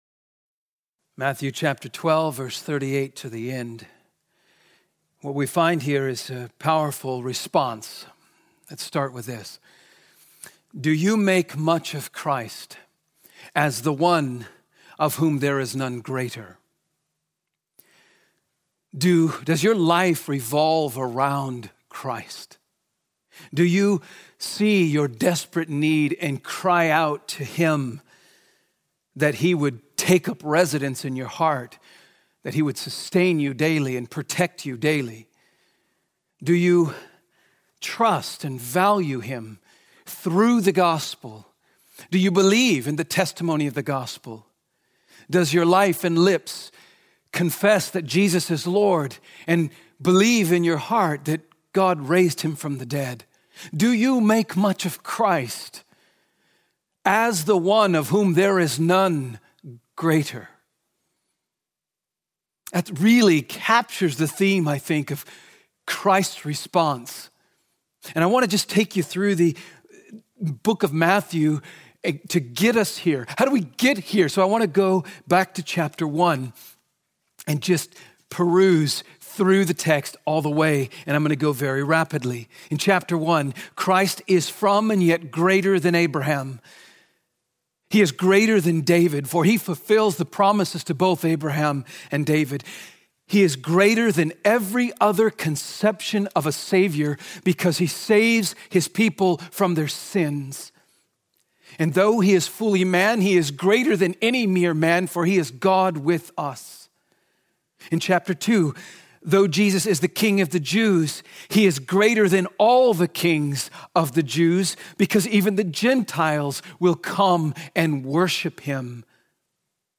Sermons - Trinity Bible Church